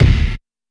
Kick (Pigs).wav